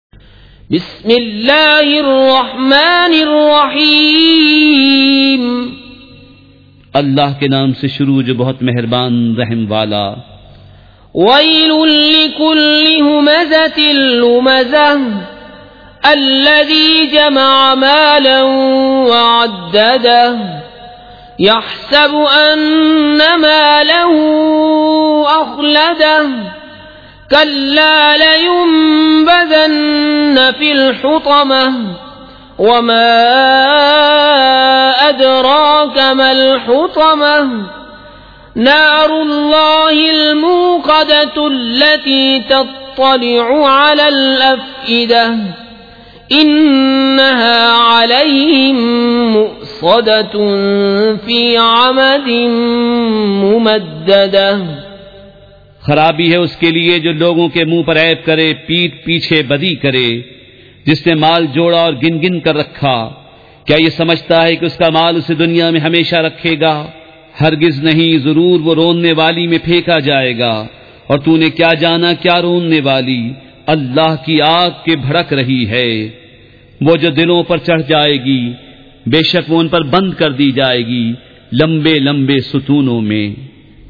سورۃ الھمزۃ مع ترجمہ کنزالایمان ZiaeTaiba Audio میڈیا کی معلومات نام سورۃ الھمزۃ مع ترجمہ کنزالایمان موضوع تلاوت آواز دیگر زبان عربی کل نتائج 1989 قسم آڈیو ڈاؤن لوڈ MP 3 ڈاؤن لوڈ MP 4 متعلقہ تجویزوآراء